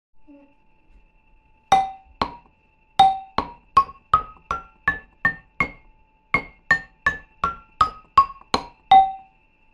ミニ バラフォン ブルキナファソ製 8音 アフリカの打楽器 （p360-06） - アフリカ雑貨店 アフロモード
演奏家用ではありませんが比較的鳴るモノをチョイスしました。あまり音量が大きくないので家で気軽に楽しんで叩けます。
工業製品と違い響きや音階にバラツキがあり完璧なものはありません。
説明 この楽器のサンプル音 原産国 材質 木、ひょうたん、革、ヒモ サイズ 長さ29cm 最大幅23cm 高さ：11cm 重量 540g コメント ※写真の商品をお届けします。